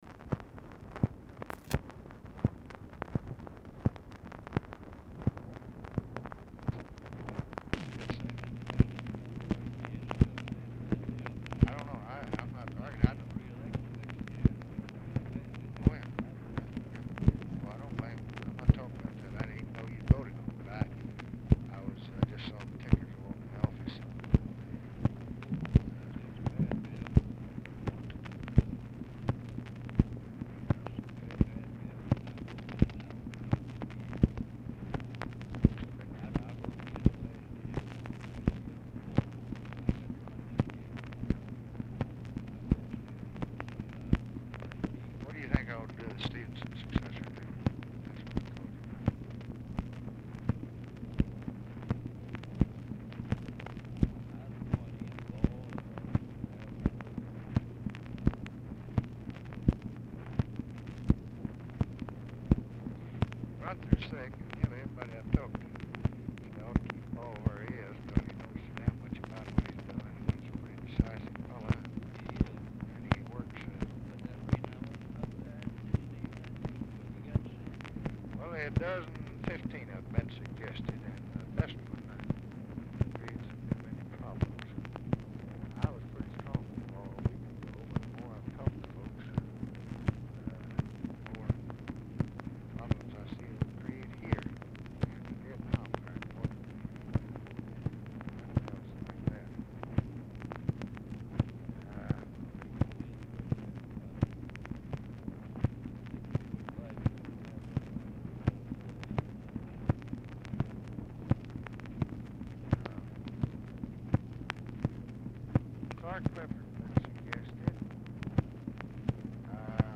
VERY POOR SOUND QUALITY; LBJ AND RUSSELL ARE ALMOST INAUDIBLE
RECORDING ENDS BEFORE CONVERSATION IS OVER
Format Dictation belt
Specific Item Type Telephone conversation